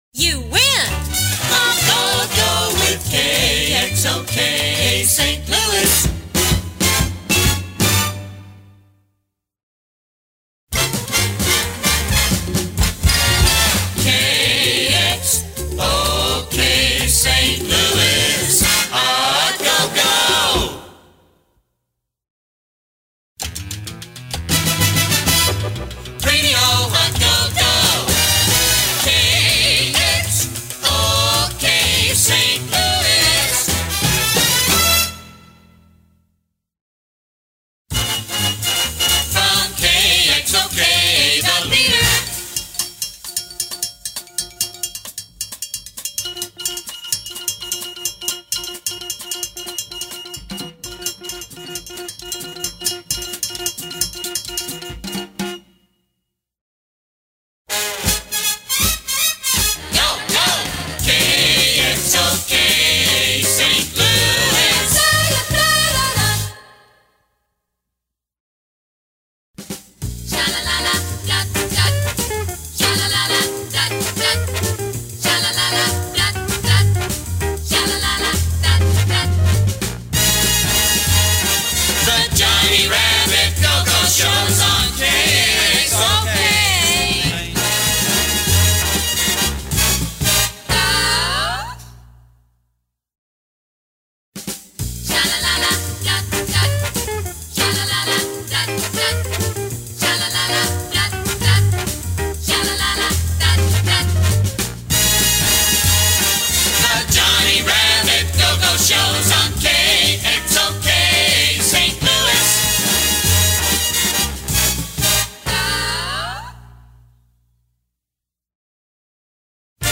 CLASSIC USA RADIO JINGLES!
Audio digitally remastered